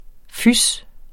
Udtale [ ˈfys ]